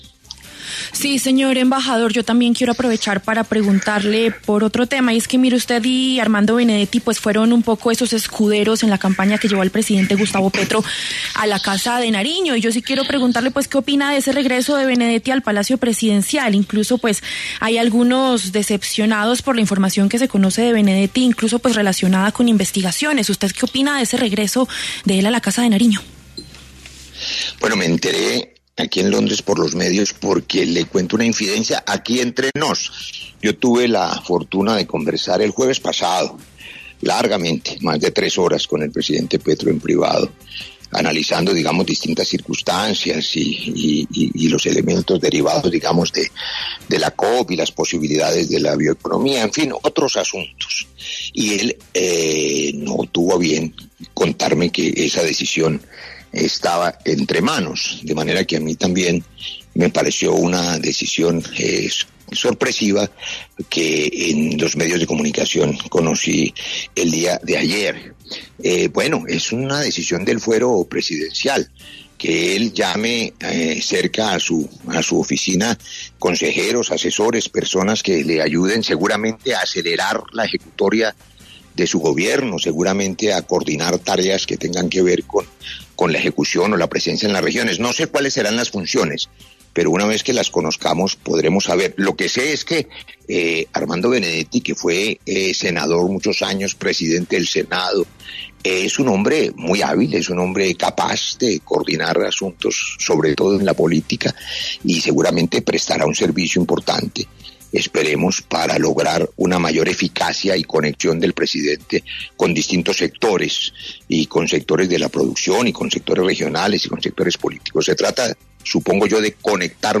En los micrófonos de La W, el embajador de Colombia en Reino Unido, Roy Barreras, quien forma parte del círculo cercano del presidente Gustavo Petro, habló sobre la llegada de Armando Benedetti a la Casa de Nariño como asesor político del mandatario.